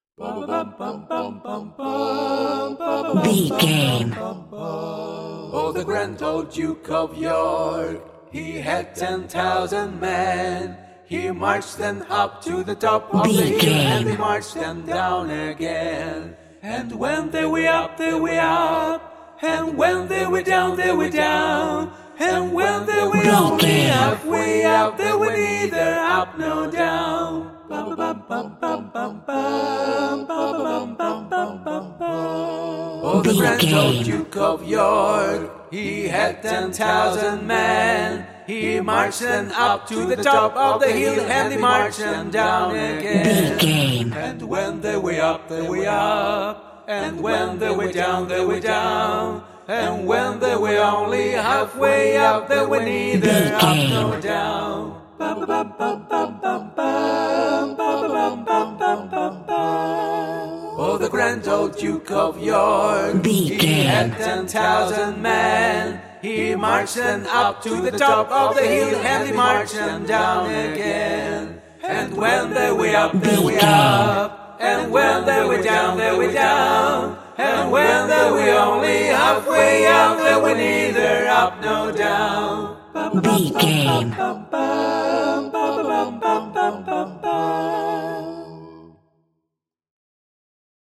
Aeolian/Minor
A♭
fun
groovy